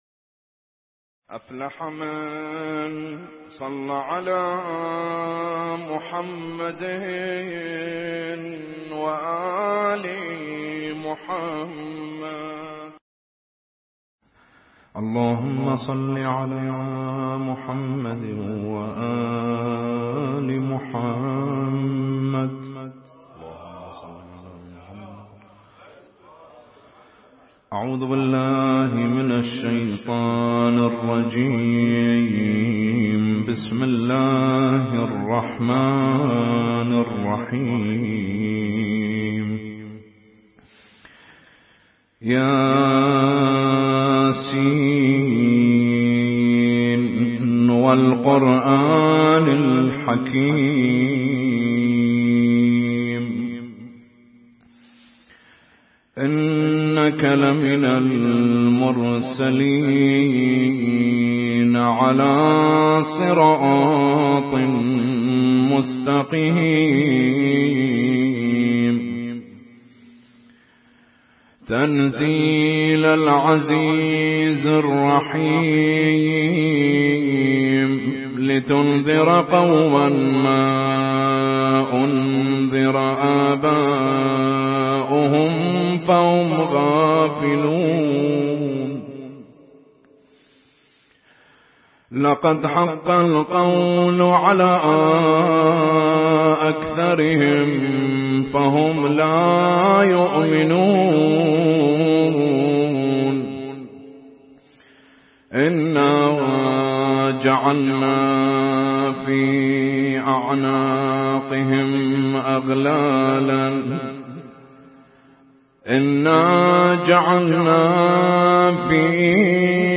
اسم التصنيف: المـكتبة الصــوتيه >> القرآن الكريم >> القرآن الكريم - القراءات المتنوعة